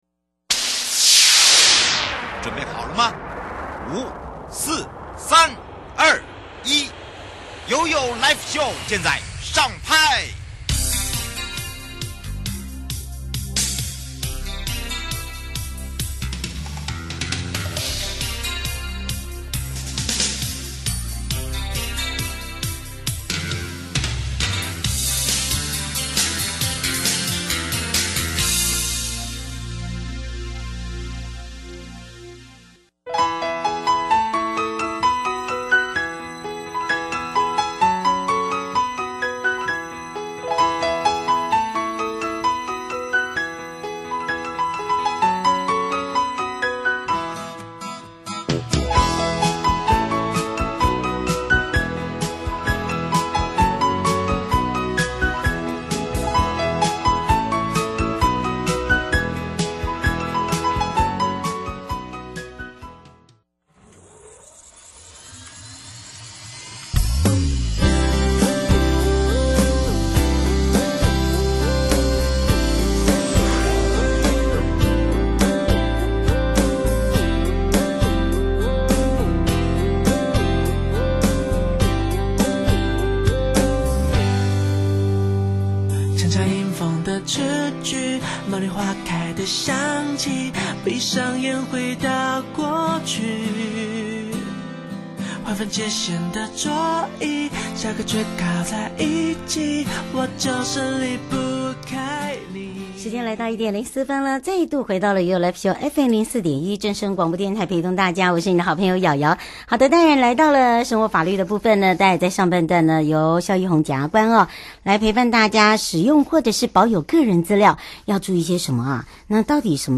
受訪者： 1. 台北地檢 蕭奕弘檢察官 2. 台北地檢 黃珮瑜主任檢察官 節目內容： 1. 使用或保有個人資料應該注意什麼?什麼是個人資料?什麼時候可以合法的使用個人資料?當事人對保有個資的單位,有什麼權力? 2. 認識家庭暴力?哪些行為屬於家庭暴力行為?那些身份關係的人可以適用家庭暴力防治法?如果已經取得保護令而違反該保護令的人,有沒有刑事責任?